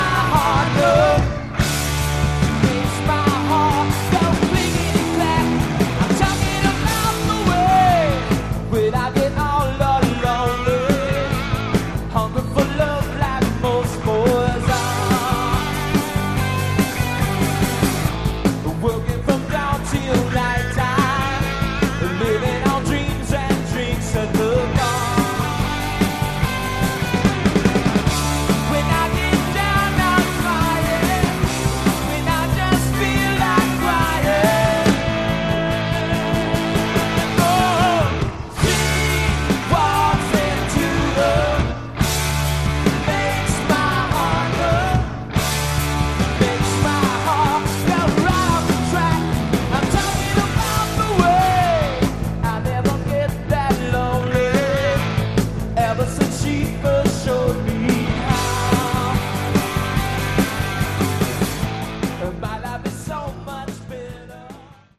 Category: Hard Rock
lead vocals
guitar
bass